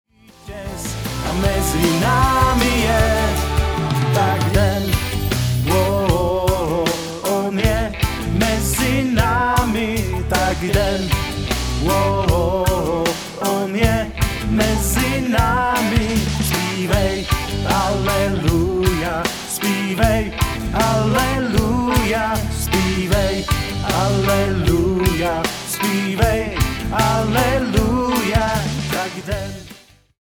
Sborový zpěv: